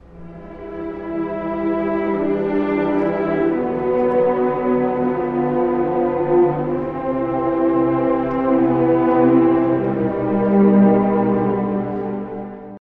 ↑古い録音のため聴きづらいかもしれません！（以下同様）
ゆったりとしたAdagioで始まり、すぐに荒々しいAllegroが登場。
低弦による沈み込むような序奏から、一気にホ短調の力強い主題へと展開します。
異国の荒波に揉まれるような音楽です！